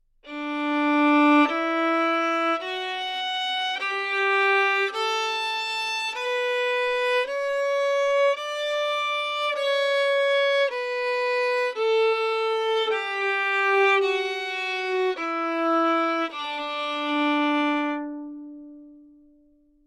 描述：三和弦D大调/C和弦用电吉他演奏，带点失真和强烈的短时延迟。
标签： 重大 处理 和弦 d长轴 吉他
声道立体声